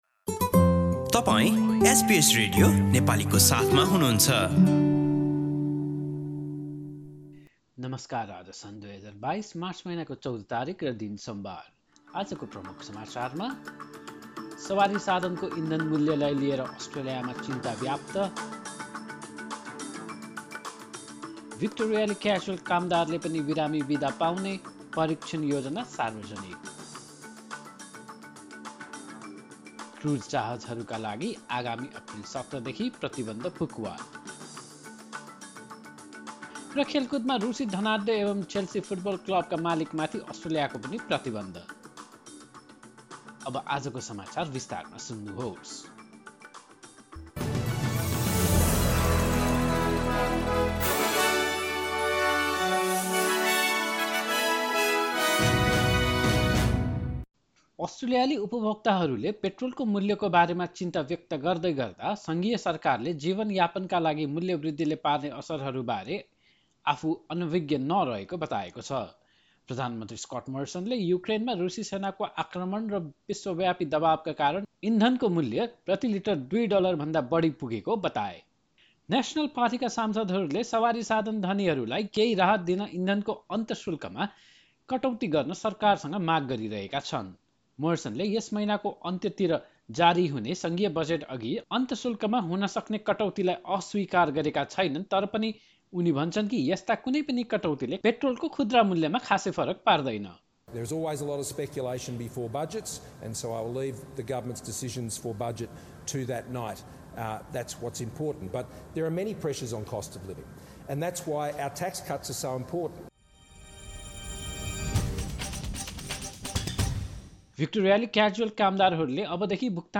Listen to the latest news headlines from Australia in Nepali. In this bulletin, concerns about the cost of fuel in Australia, Victorian casual workers can now access paid sick leave as the State government announces a 246 million trial scheme and the Federal Trade and Tourism Minister Dan Tehan says the cruising industry will be able to resume on 17 April.